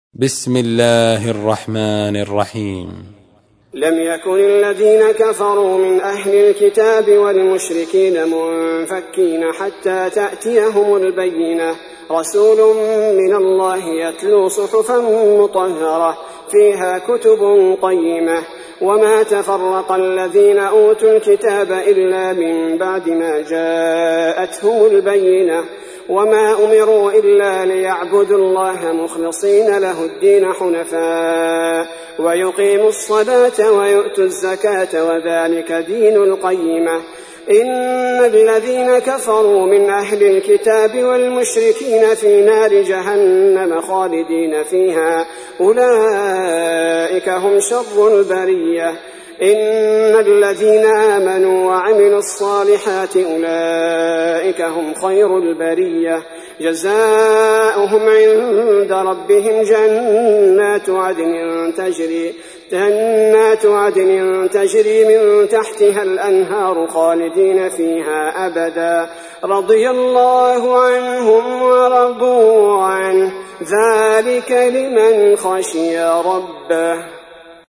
تحميل : 98. سورة البينة / القارئ عبد البارئ الثبيتي / القرآن الكريم / موقع يا حسين